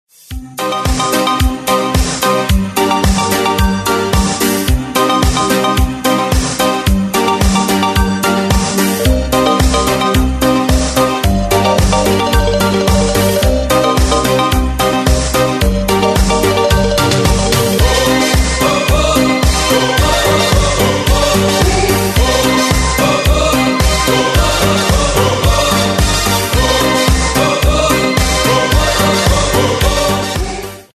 80-е